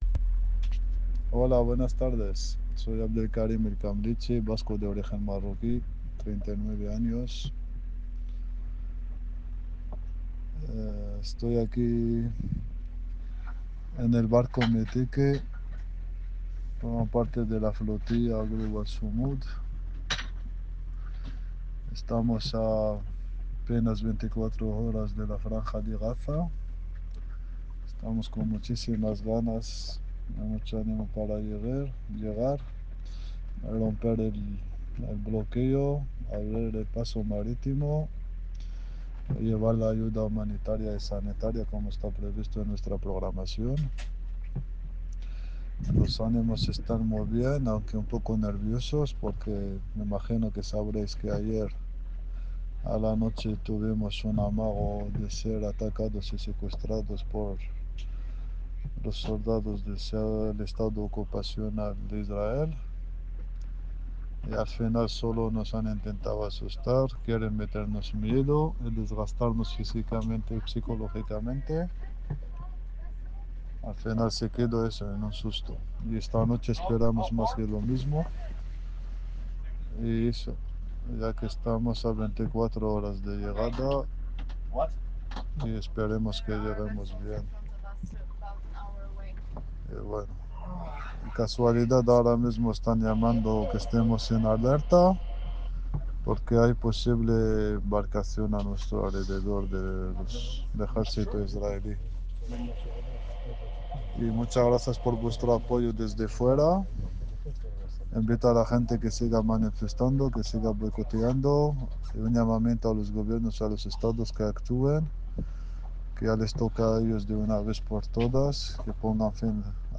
Barco Metique. 1 octubre de 2025.